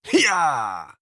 Effort Sounds
19. Effort Grunt (Male).wav